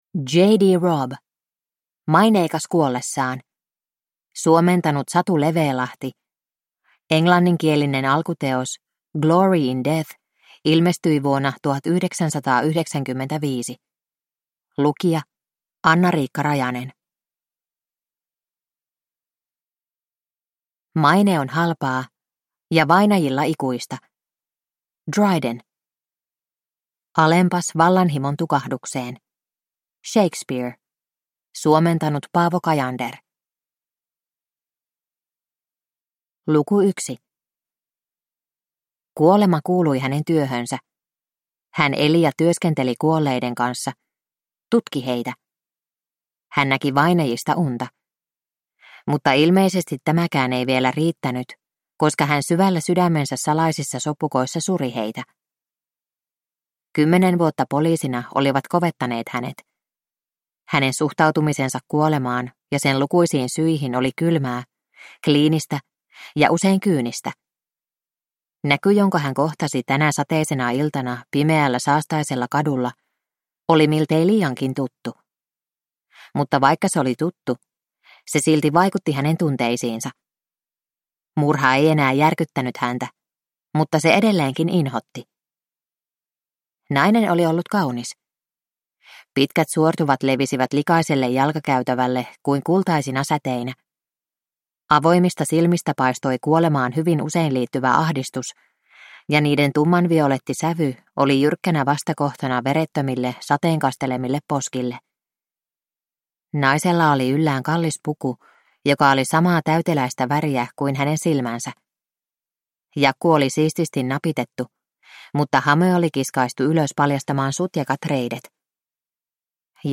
Maineikas kuollessaan (ljudbok) av Nora Roberts